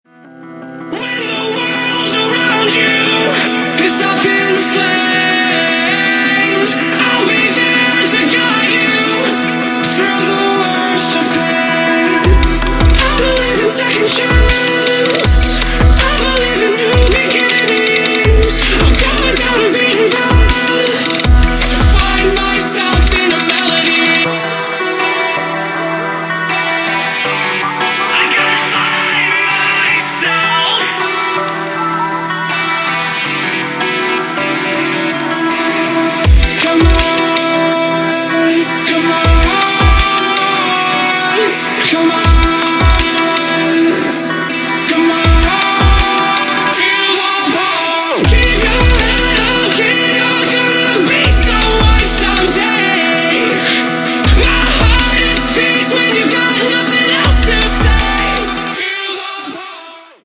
• Качество: 128, Stereo
мужской вокал
EDM
club
Rock
vocal